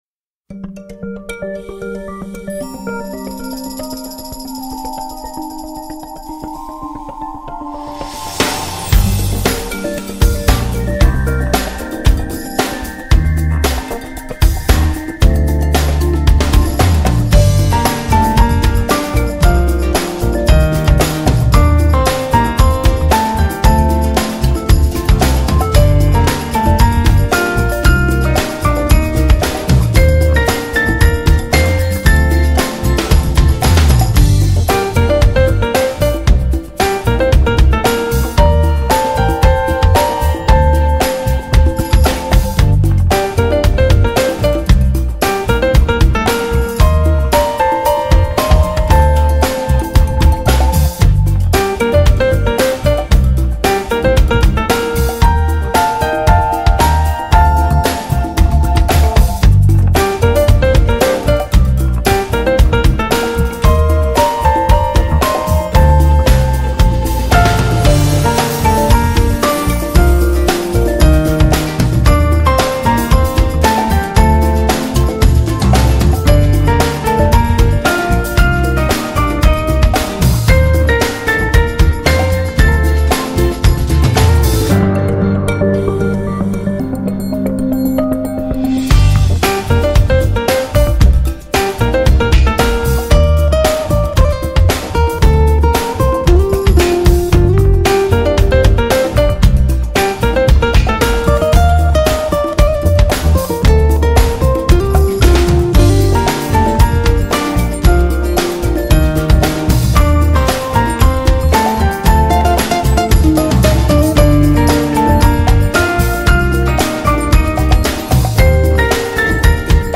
موسیقی اینسترومنتال
موسیقی بی کلام